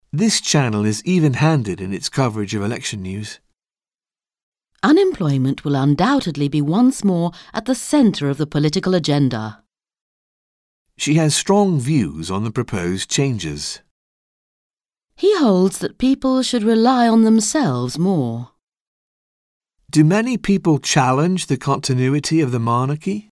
Un peu de conversation- Les institutions démocratiques